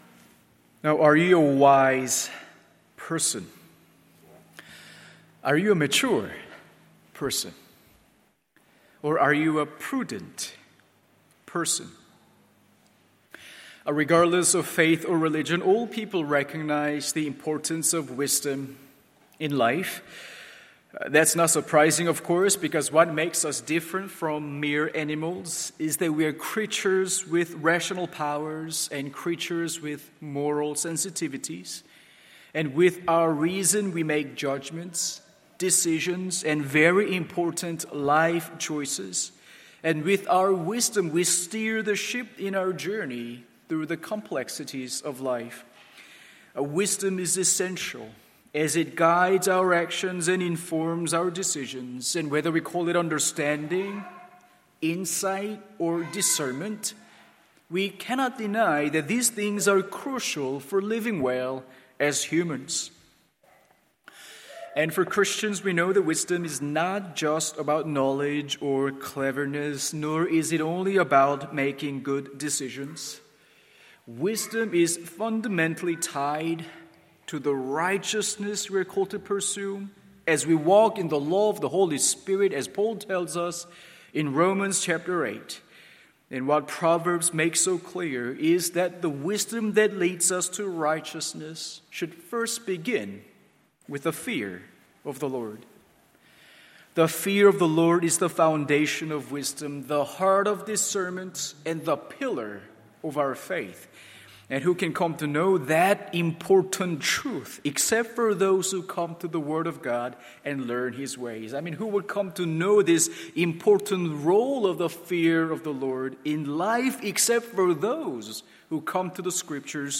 MORNING SERVICE Proverbs 1:1-19…